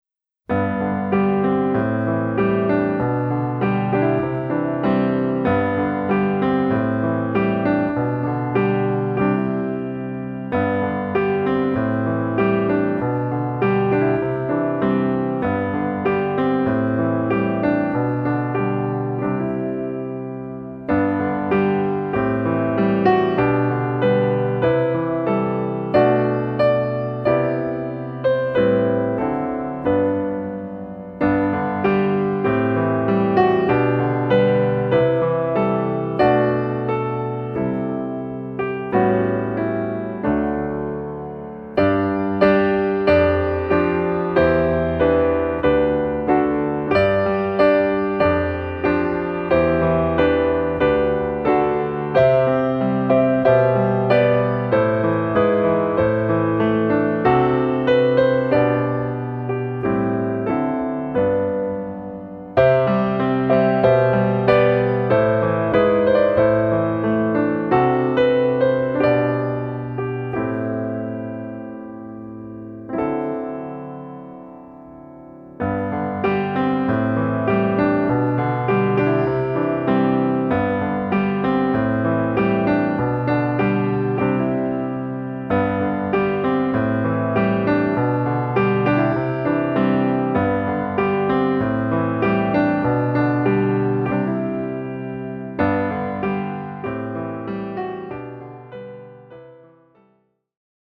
Level : Easy | Key : G | Individual PDF : $3.99